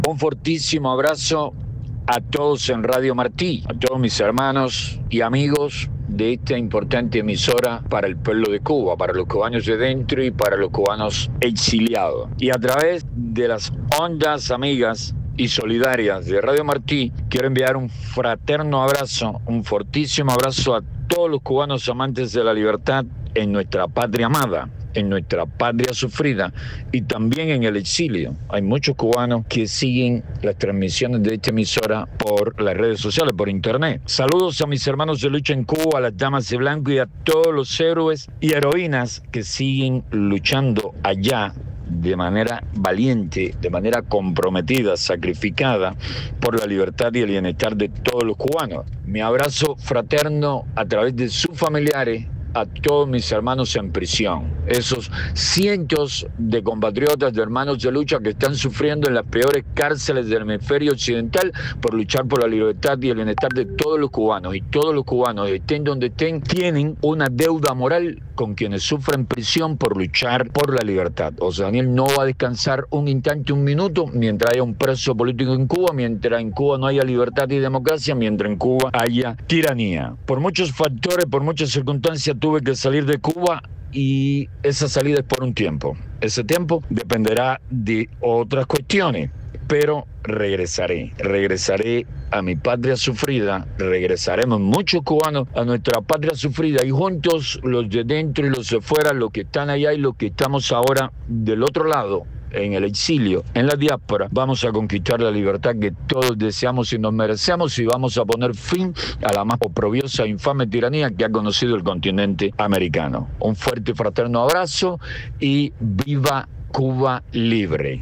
José Daniel Ferrer en conversación exclusiva con Martí Noticias